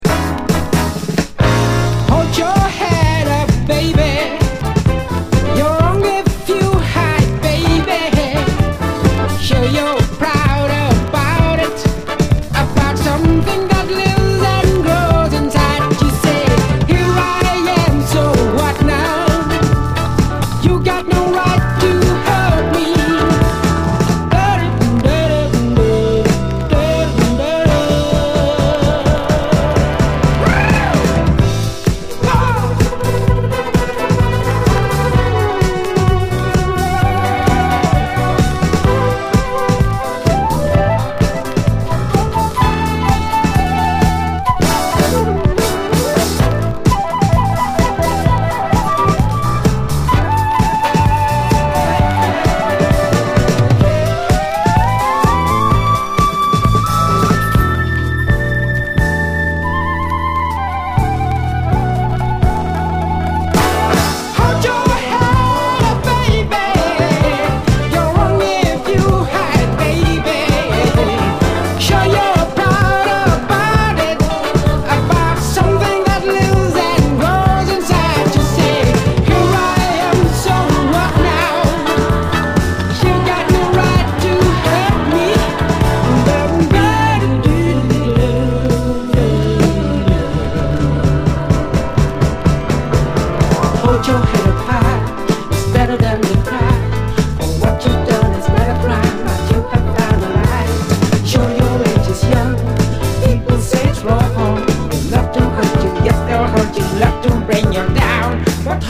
洒脱なムードのナイス・オールドタイミー・ディスコ！